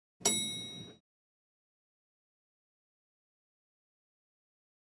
Elevator Bell Dings Soft, Medium Close Perspective.